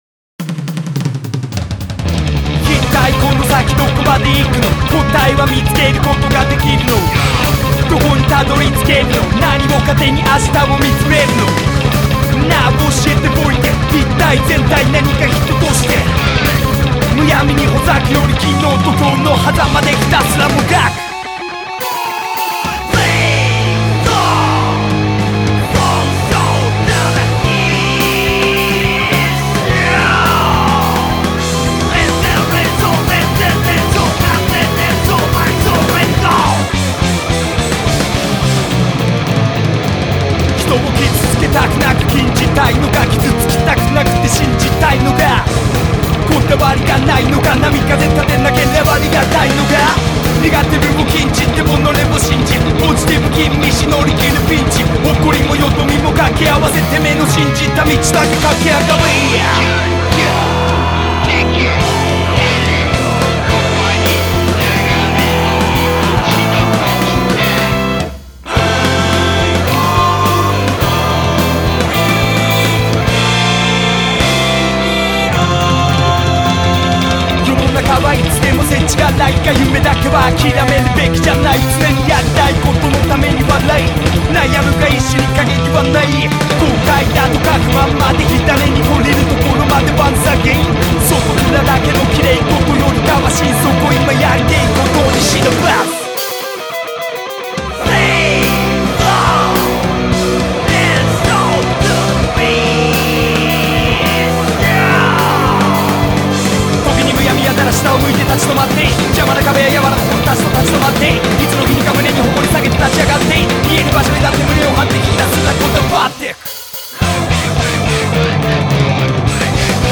BPM107-213
Audio QualityPerfect (High Quality)
Genre: HIP ROCK 5.